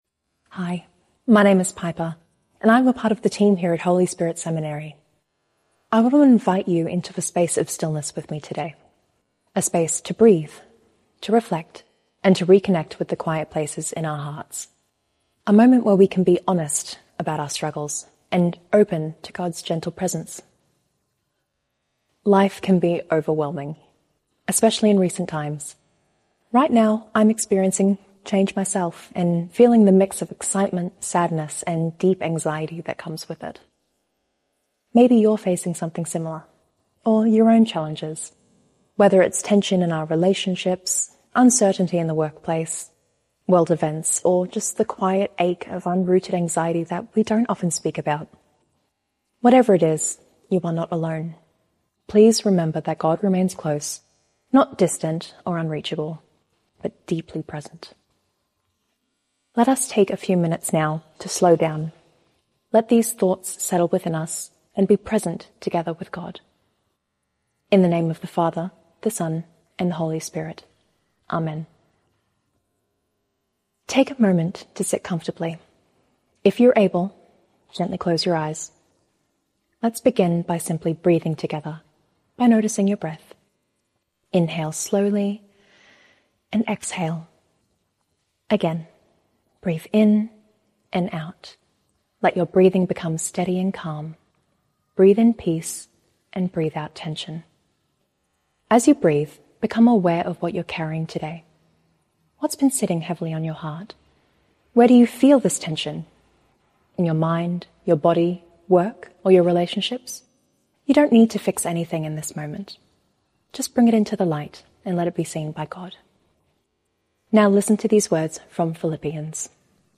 Come, let us pray together.